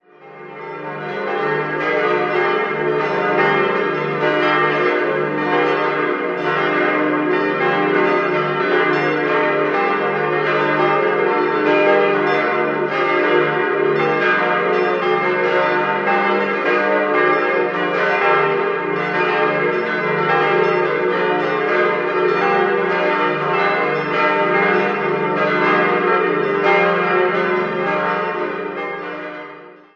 Herz-Jesu-Glocke es' 1.724 kg 1989 Karlsruher Glocken- und Kunstgießerei Augustinerglocke ges' 982 kg 1989 Karlsruher Glocken- und Kunstgießerei Märtyrerglocke as' 706 kg 1989 Karlsruher Glocken- und Kunstgießerei Wohltäterglocke b' 495 kg 1989 Karlsruher Glocken- und Kunstgießerei Peter-und-Paul-Glocke c'' 354 kg 1504 unbezeichnet (Leihglocke) Marienglocke es'' 193 kg 1954 Karl Czudnochowsky, Erding Johannes-d.-T.-Glocke f'' ?? kg 1954 Karl Czudnochowsky, Erding